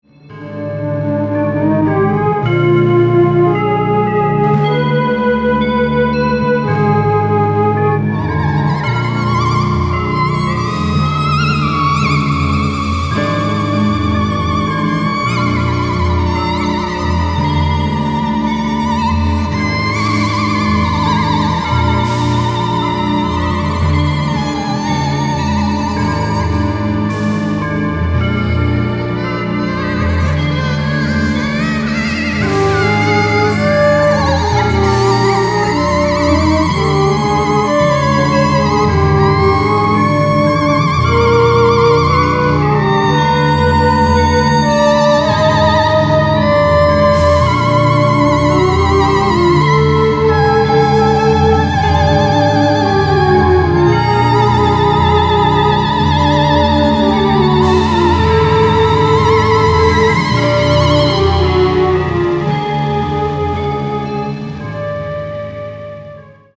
Alto Saxophone
Balalaika, Drum Machine